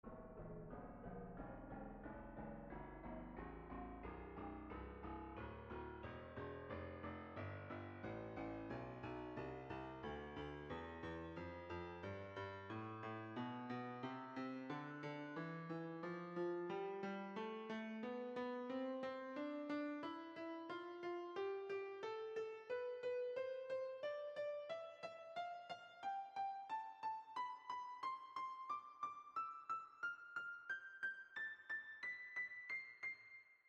ピアノの低音から高音へ、左右交互に、音が鳴ります。
両耳の聞こえが同じ場合は、どちらの耳にも同じ音量で聞こえます。